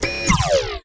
alaser.ogg